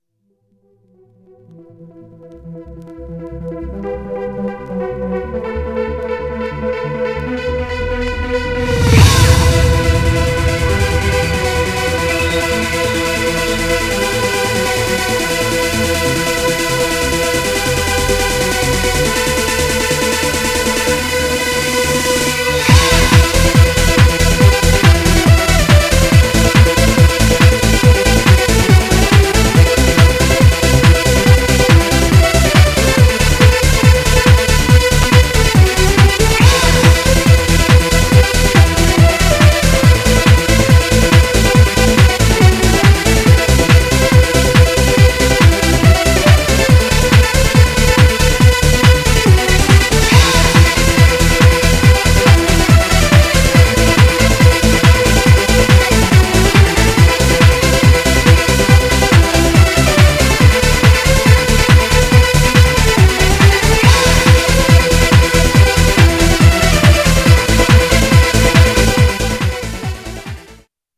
Styl: Drum'n'bass, Trance